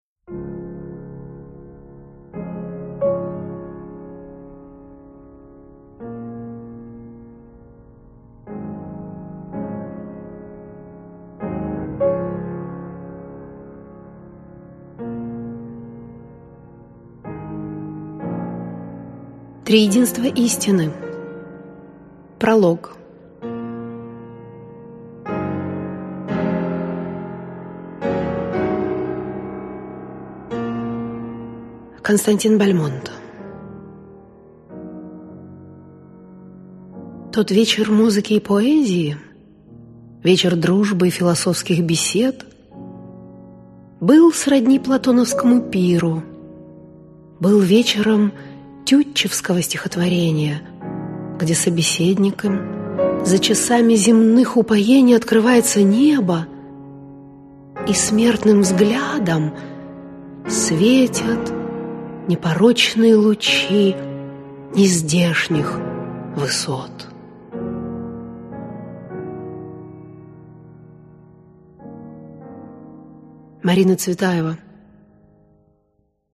Аудиокнига Философские трилистники «Триединство истины» | Библиотека аудиокниг
Прослушать и бесплатно скачать фрагмент аудиокниги